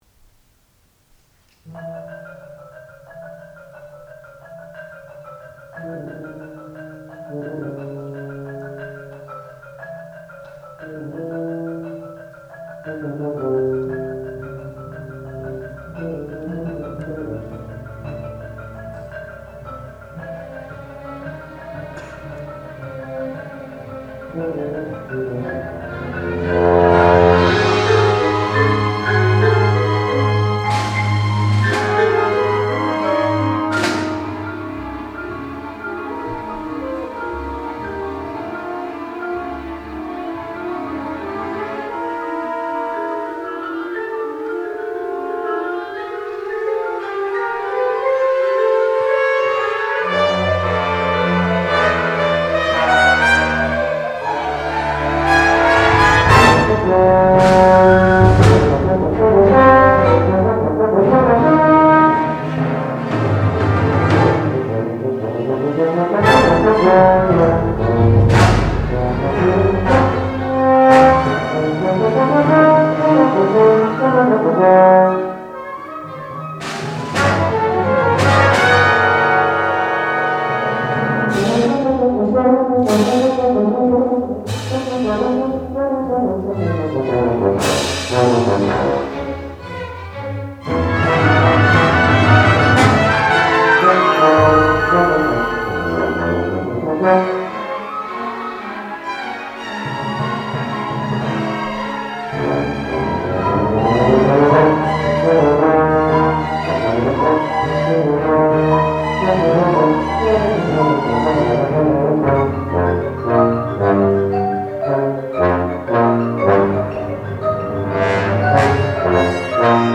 Three Scenes from Moby Dick, mvt. 3 for wind ensemble, 2013